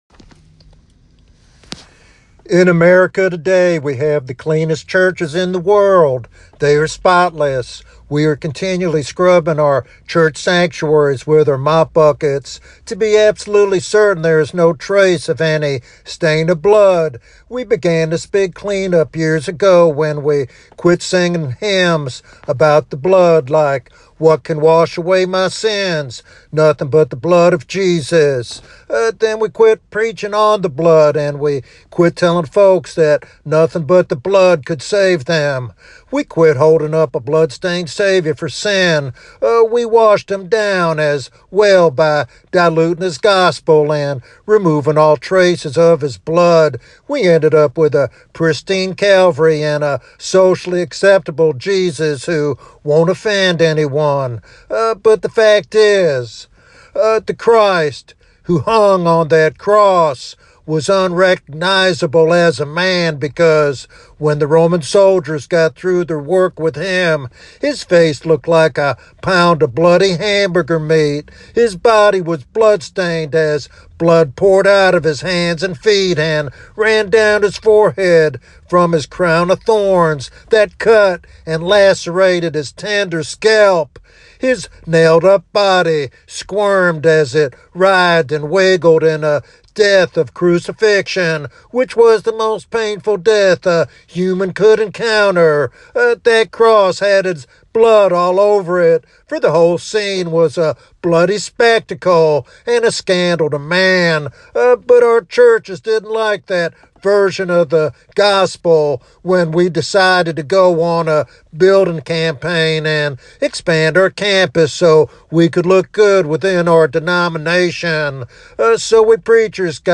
In this powerful evangelistic sermon